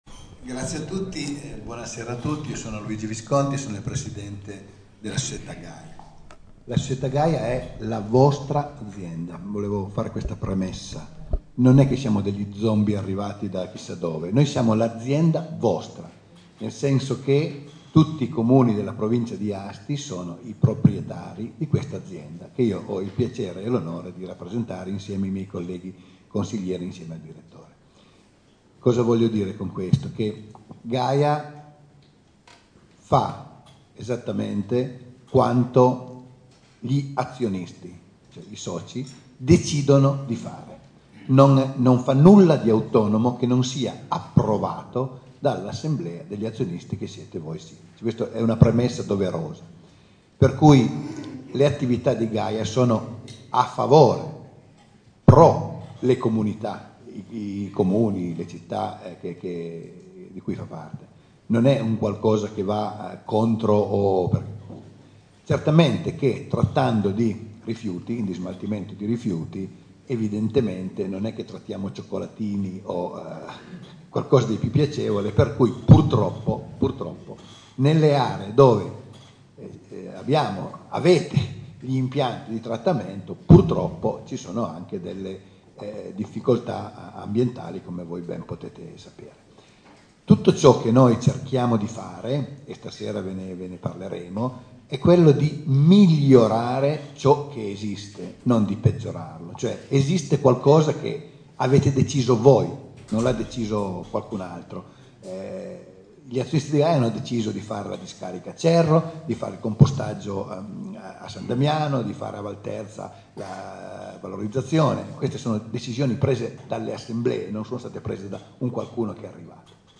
A nove mesi dal primo appuntamento, mercoledì 21 marzo 2015 – presso il teatrino parrocchiale “Beppe Olivetti” di Cisterna d’Asti - il Polo Cittattiva per l’Astigiano e l’Albese ha organizzato – in collaborazione con il Museo Arti e Mestieri di un Tempo e al Comune di Cisterna d’Asti - un secondo incontro pubblico in relazione alle possibili trasformazioni dell’ impianto di Compostaggio di S. Damiano d’Asti. Era presente il Consiglio di Amministrazione dell’azienda Gaia, sindaci, vicesindaci, amministratori dei comuni interessati, l’assessore provinciale Luca Quaglia e numerosi abitanti.